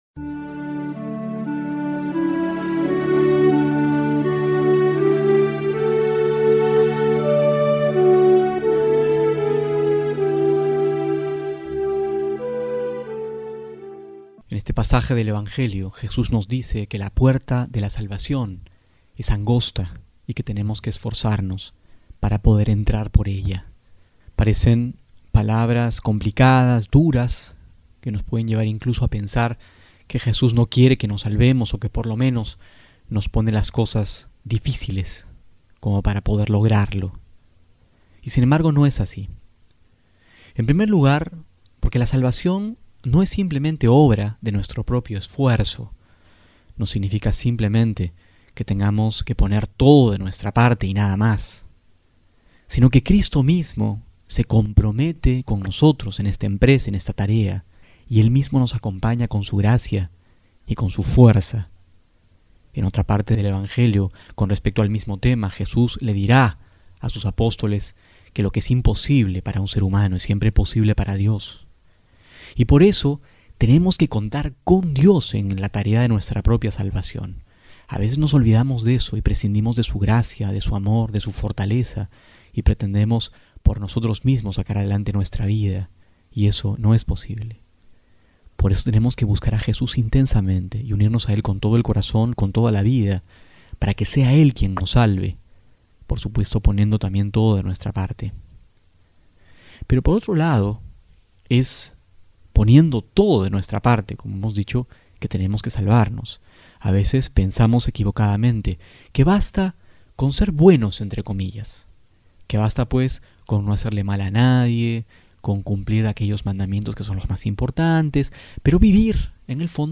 octubre31-12homilia.mp3